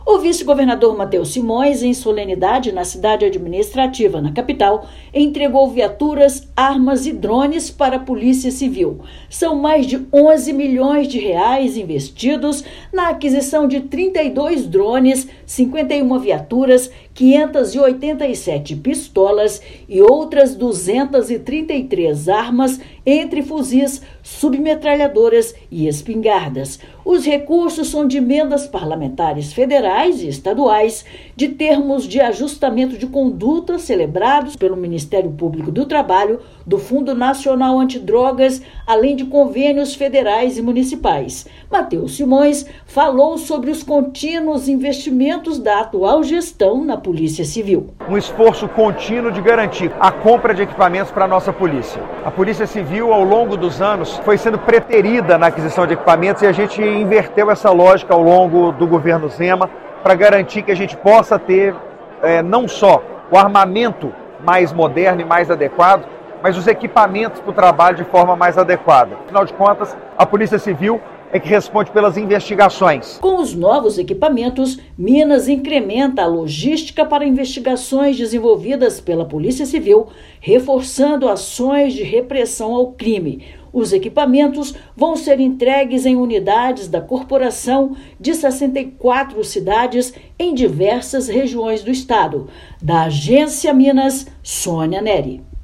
Equipamentos, avaliados em mais de R$ 11 milhões, vão reforçar a segurança em 64 cidades do estado. Ouça matéria de rádio.